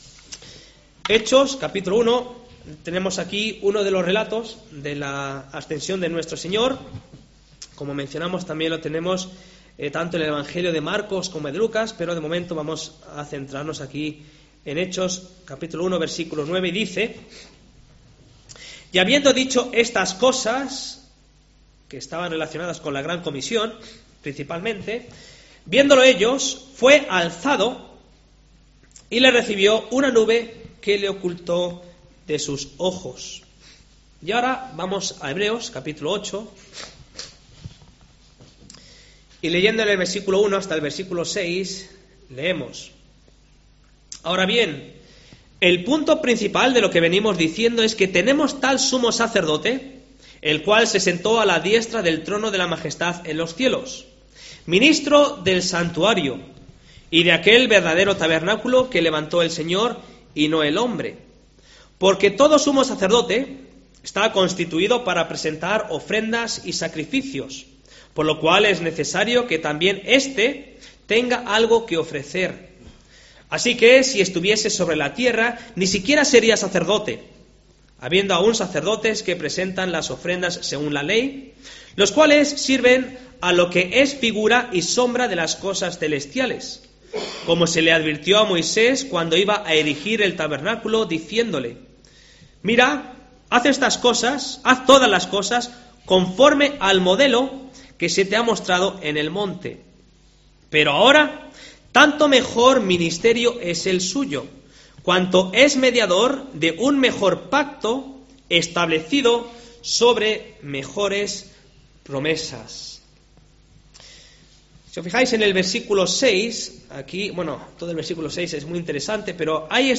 Listado Últimos Sermones